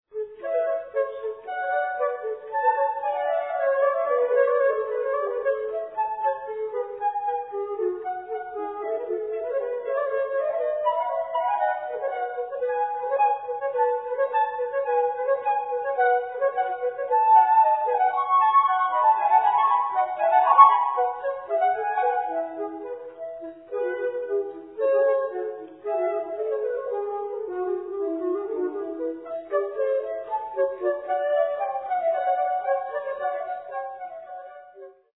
sonata for 2 flutes No. 1 in B flat major
Presto - 1:50